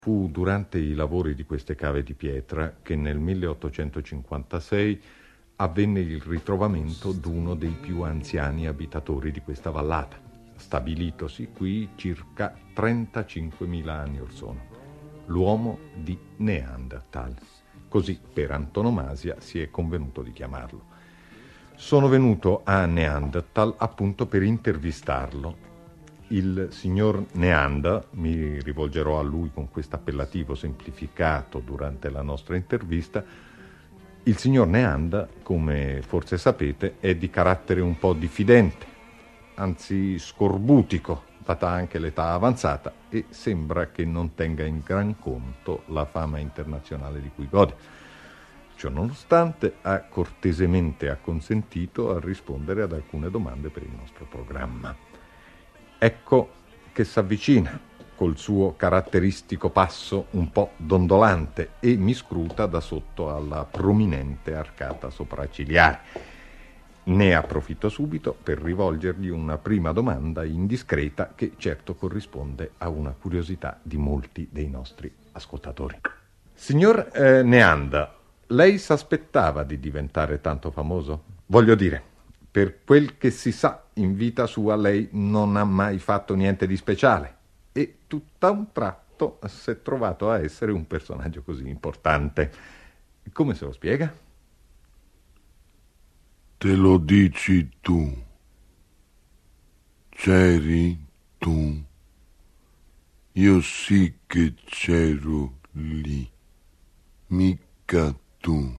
Dalle interviste impossibili: Italo Calvino intervista l'Uomo di Neanderthal